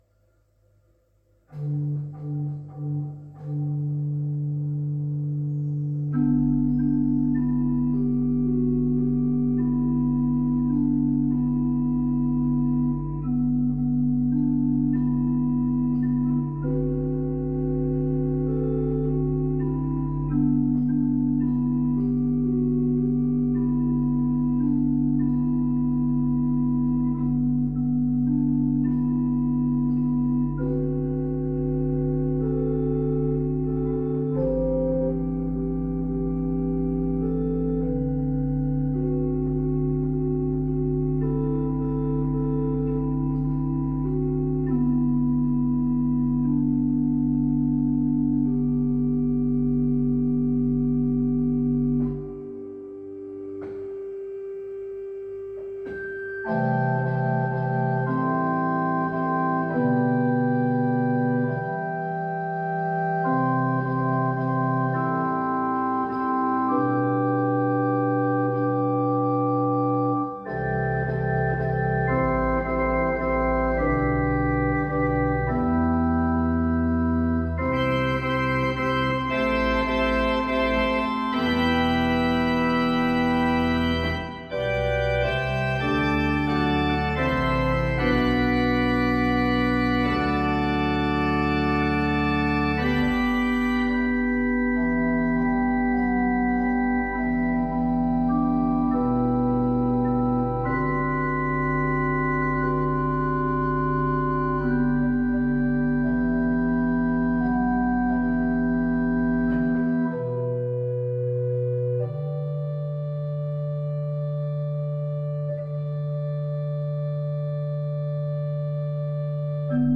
Kirkon nykyiset urut ovat alkuaan suomalaiset, Kangasalan urkutehtaan vuonna 1962 rakentamat ja vuonna 1990 Urkurakentamo Hans Heinrichin Vormsiin pystyttämät ja uudistamat.